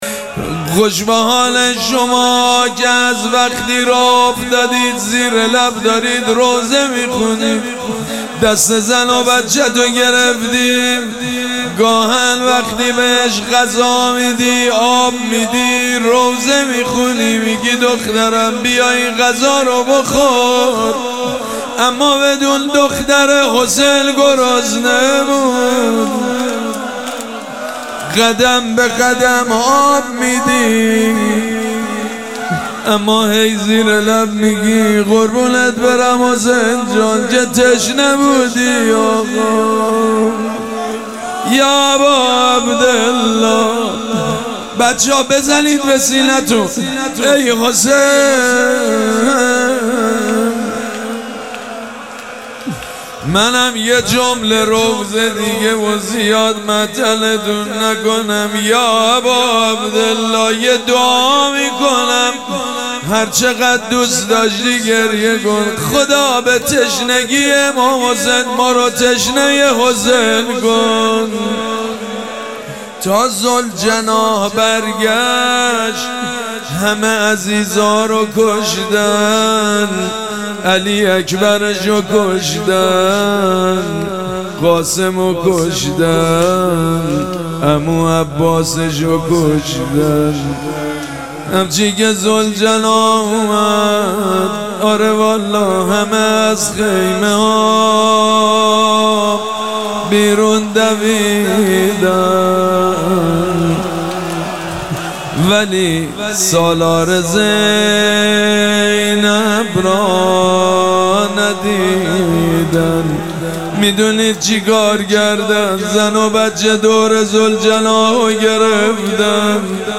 شب دوم مراسم عزاداری اربعین حسینی ۱۴۴۷
موکب ریحانه الحسین سلام الله علیها
روضه